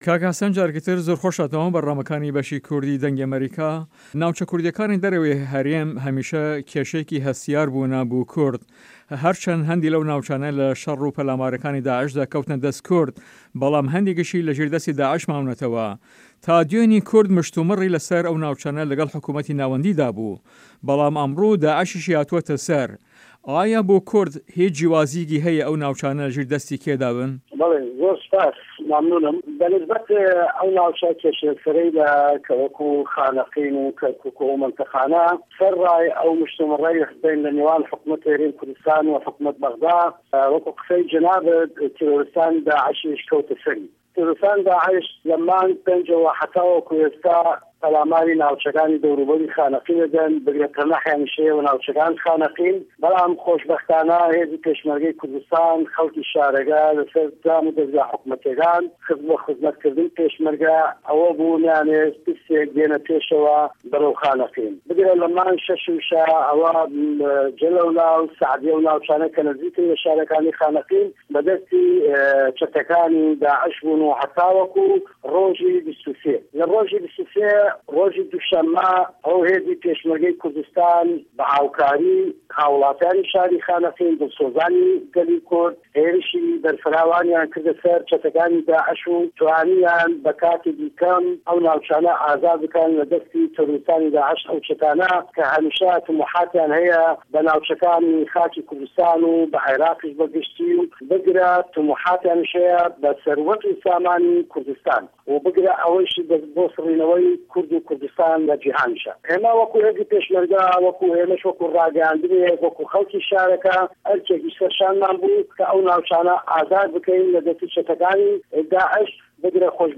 هه‌رێمه‌ کوردیـیه‌کان - گفتوگۆکان
له‌ هه‌ڤپه‌ێڤینێکدا له‌گه‌ڵ به‌شی کوردی ده‌نگی ئه‌مه‌ریکا